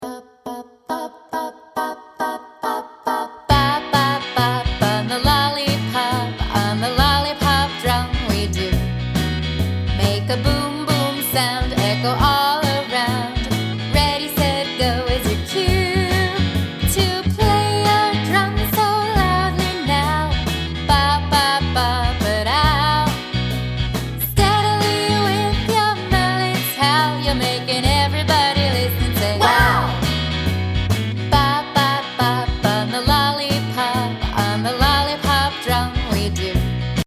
Instrument Songs For Children